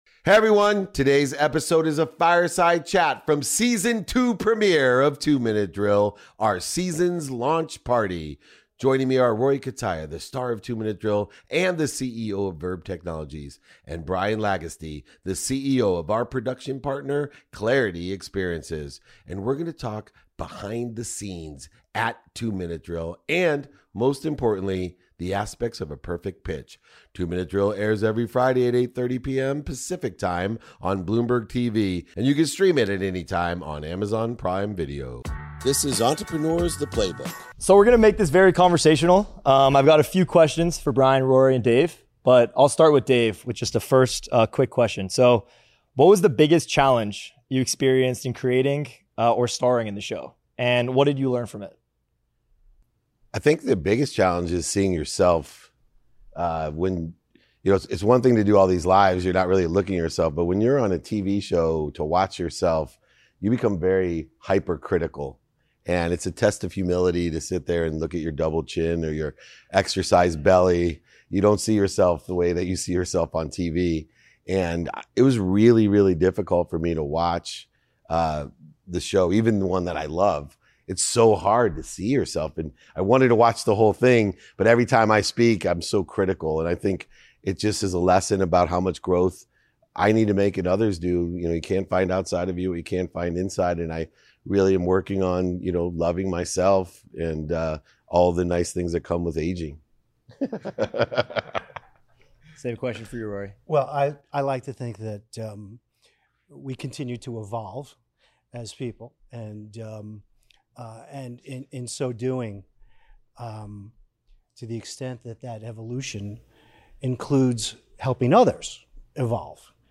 Today's episode is a small fireside chat we had at the launch party for the second season of 2 Minute Drill.